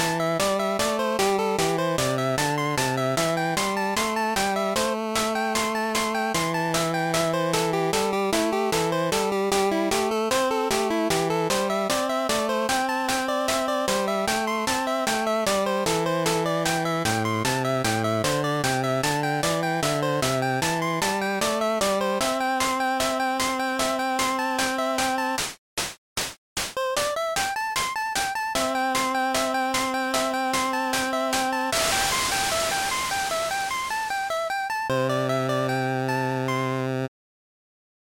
标签： 快乐 象素 音乐
声道立体声